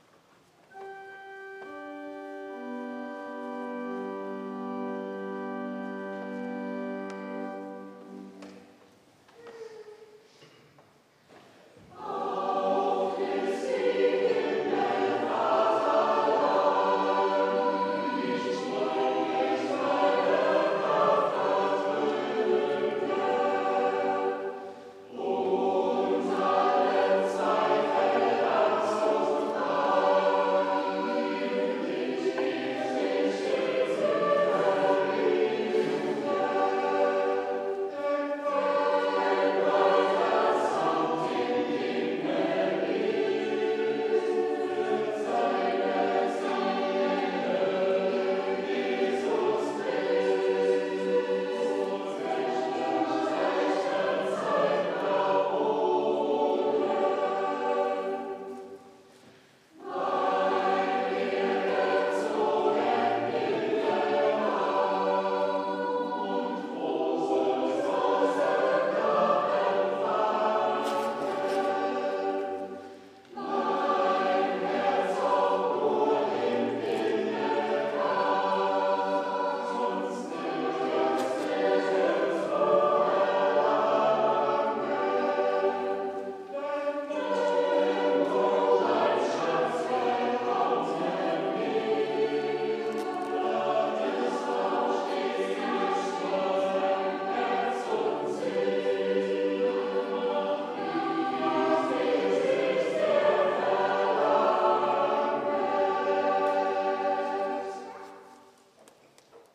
Auf Christi Himmelfahrt allein... Chor der Evangelisch-Lutherische St. Johannesgemeinde Zwickau-Planitz
Audiomitschnitt unseres Gottesdienstes am Himmelfahrtstag 2023.